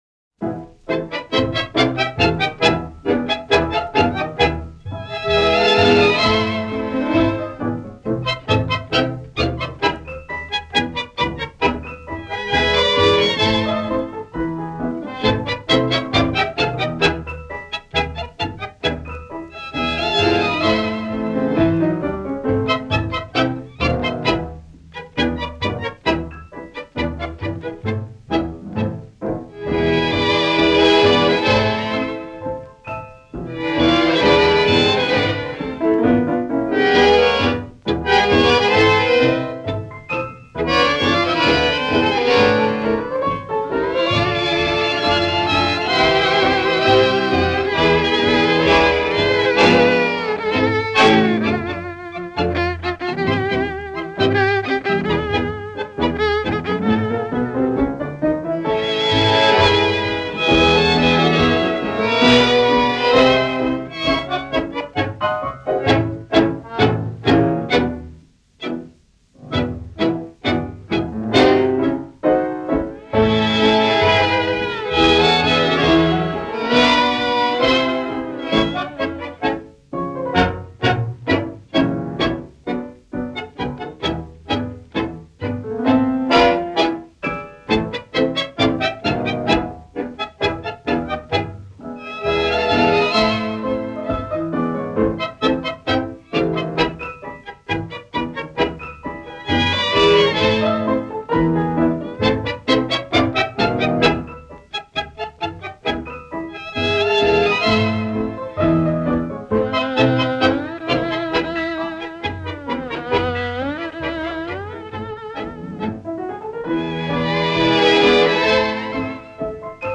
Галерея Танго дня